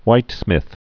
(wītsmĭth, hwīt-)